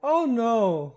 Add voiced sfx
ohno6.ogg